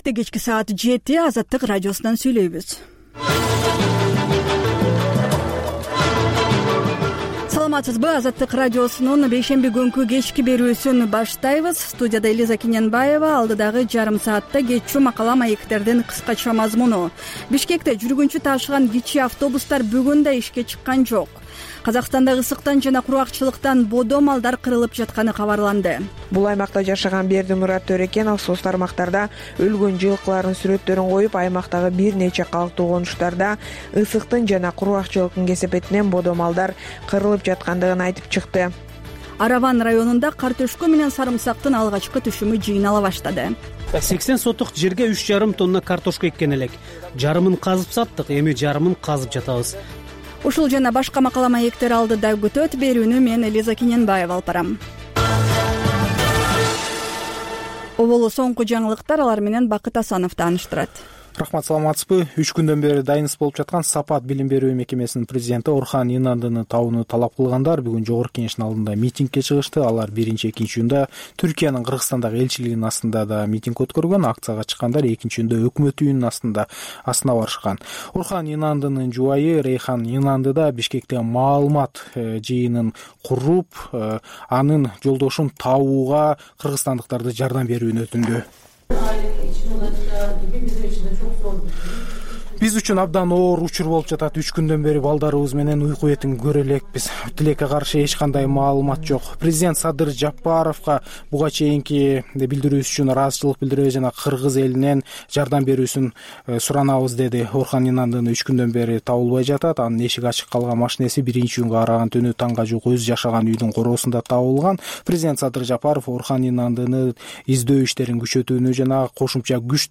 Бул үналгы берүү ар күнү Бишкек убакыты боюнча саат 19:00дан 20:00га чейин обого түз чыгат.